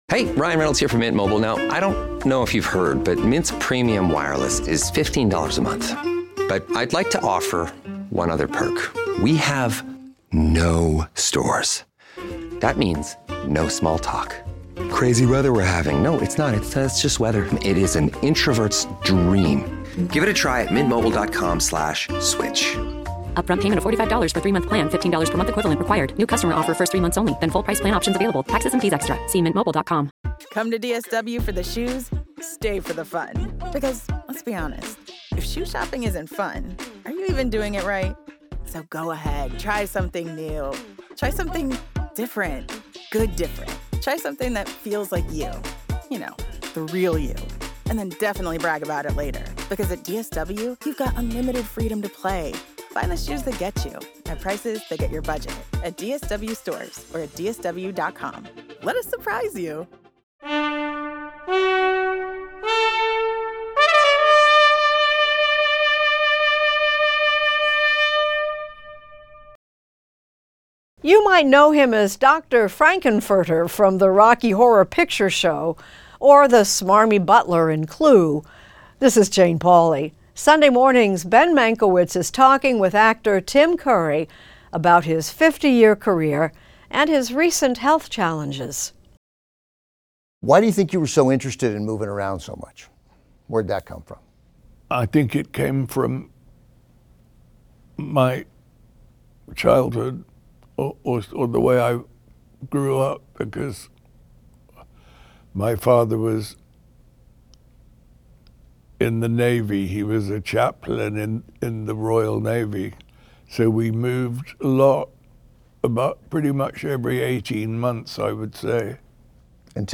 Extended Interview: Tim Curry
In 2012 actor Tim Curry suffered a stroke, after which he had to relearn how to speak. In this exclusive, the steadfastly private Curry talks with Turner Classic Movies host Ben Mankiewicz about his memoir "Vagabond," and his life, career, and medical story.